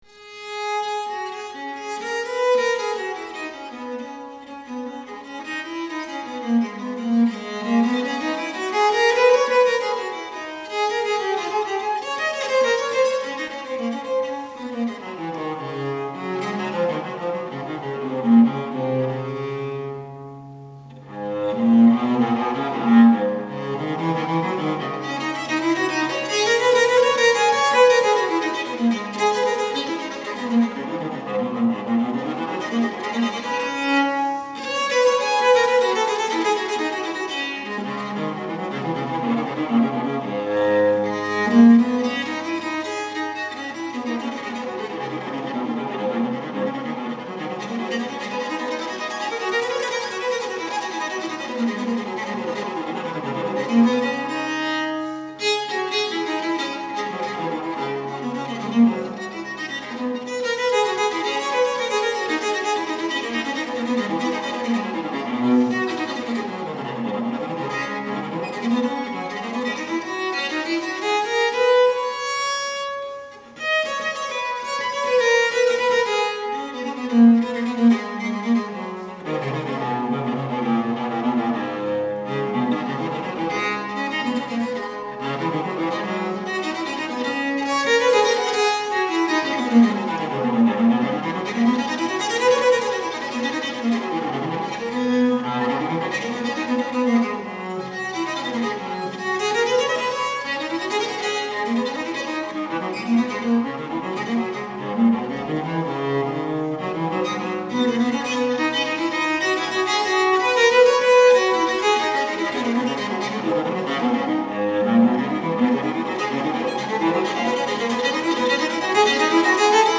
viola bastarda
Viola Bastardaのためのリチェルカール